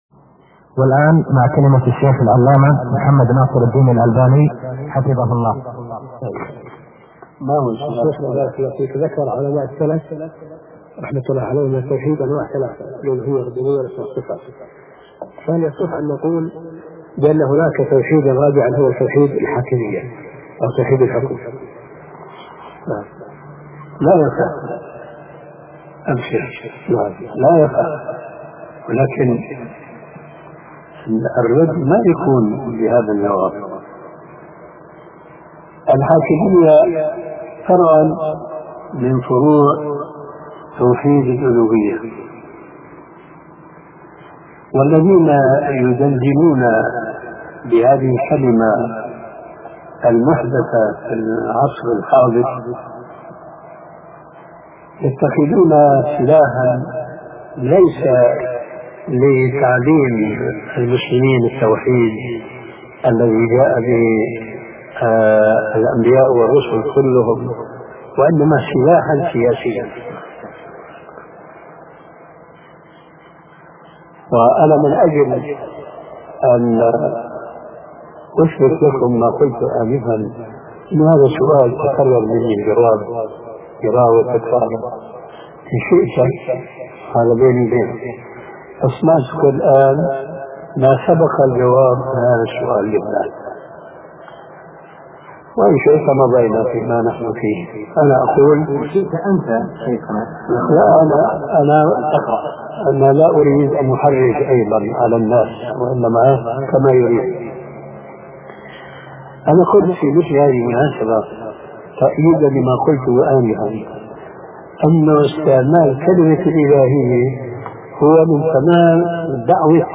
شبكة المعرفة الإسلامية | الدروس | توحيد الحاكمية |محمد ناصر الدين الالباني